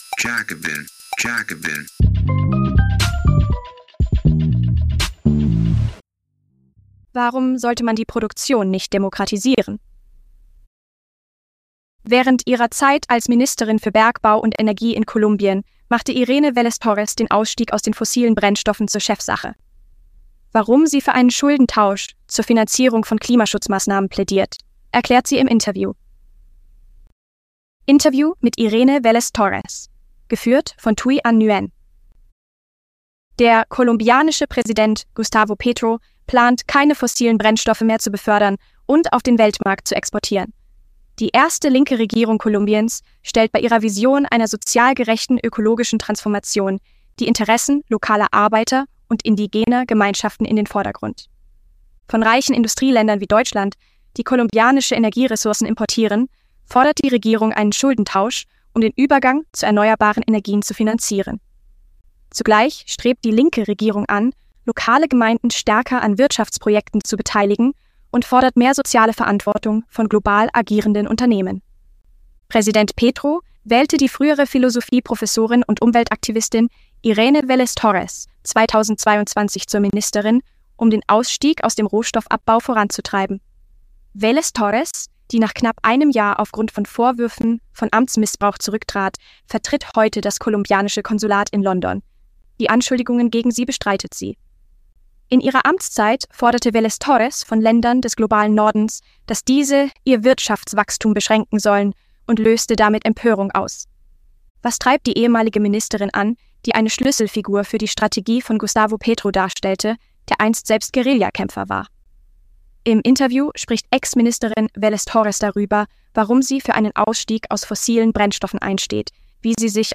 – Interview mit Irene Vélez-Torres